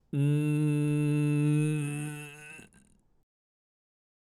今回の練習のゴールとしては、グーの声から力を入れる（胸や肩周り、腹筋などに力を入れて、息の流れを止めるイメージ）ことでグーの声がブレる・グーの声にノイズ（ジリジリ感）が増える、などの状態が起きればOKですよ～！
まずは、G(ん)+仮声帯(普通からじりじり入れる)